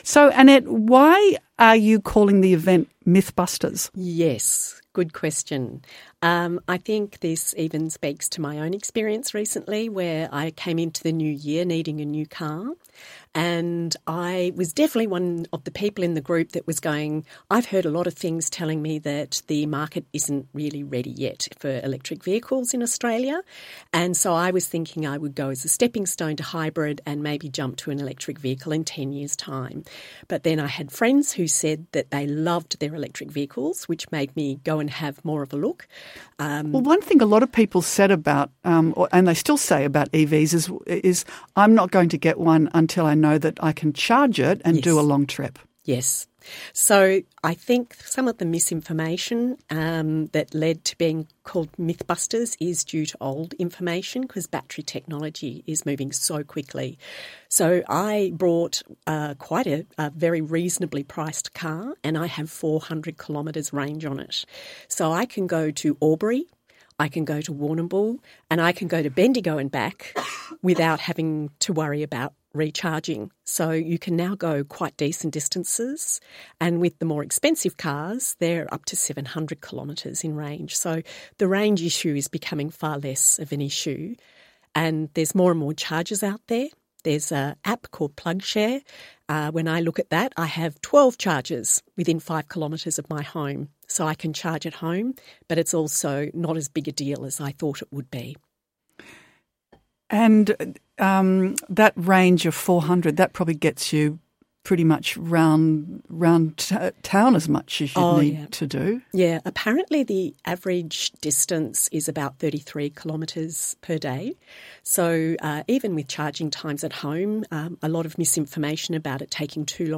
Electric Vehicle Mythbusters event-interview podcast – 88.3 Southern FM